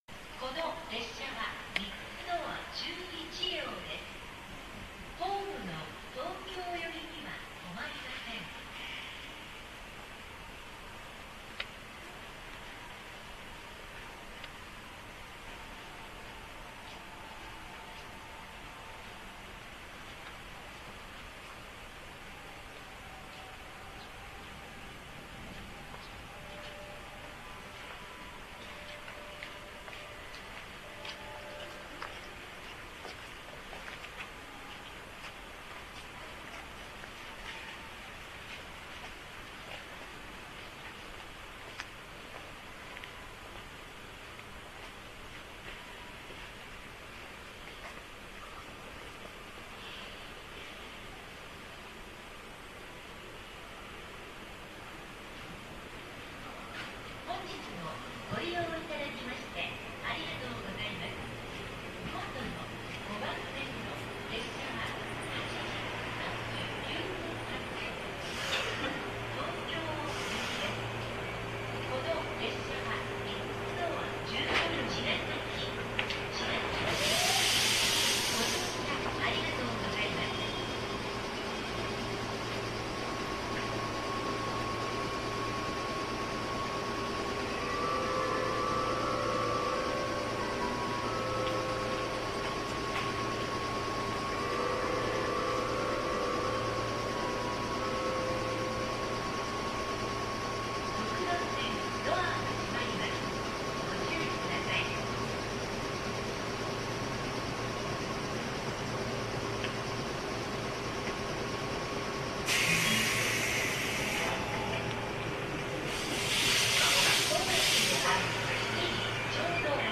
走行音収録の合間などに録った音、偶然マイクに入った音などを、特に意味もなく公開していきます。
実際に駅のホームに居る気分で聞いてみてください。
今回は茅ヶ崎駅にて。
重たい音と共に冒頭の「3つドア11両」という放送も聞けなくなりましたね。
本当はホームの真ん中に居るのがいいんでしょうが、端のほうにいたのと丁度いいタイミングで上下が来てくれたので、下りの発車と上りの到着でワンセットにしてみました。
茅ヶ崎駅113系発着風景